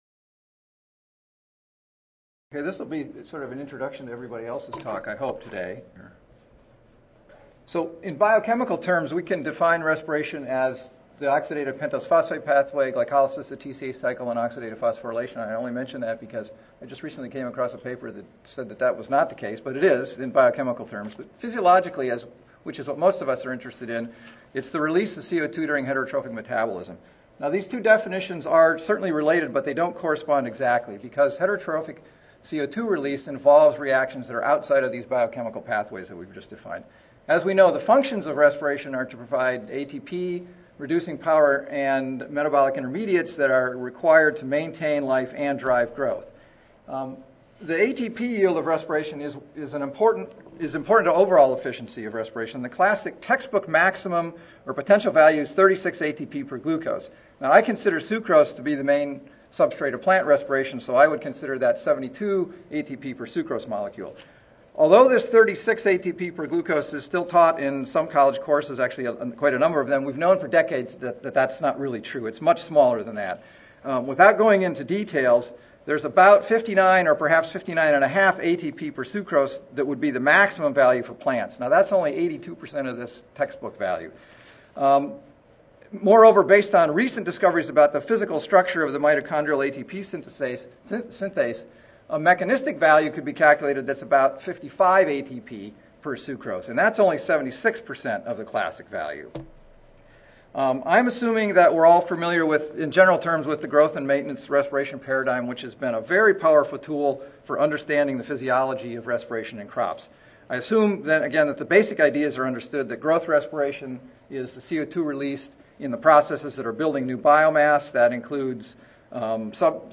University of Sydney Recorded Presentation Audio File 9:15 AM 101-2 Root Respiration Metabolism Associated With Heat Tolerance in Perennial Grass Species.